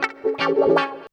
137 GTR 5 -R.wav